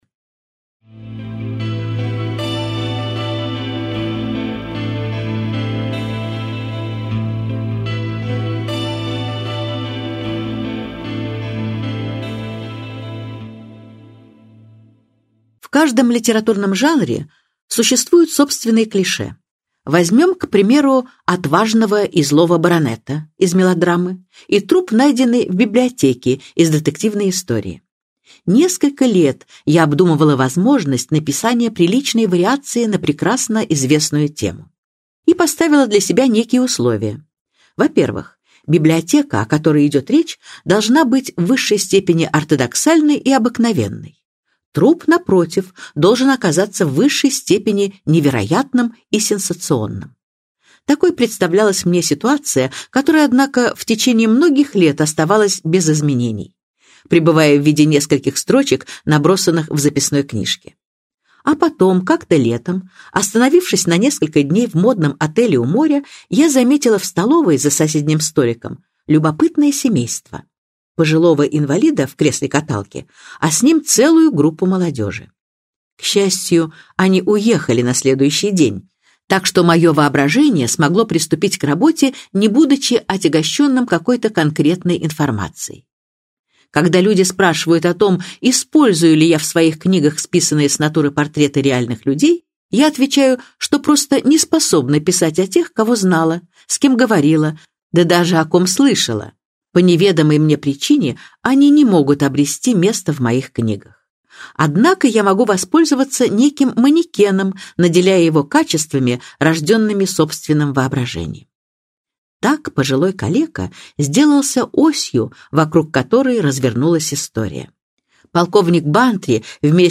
Аудиокнига Труп в библиотеке - купить, скачать и слушать онлайн | КнигоПоиск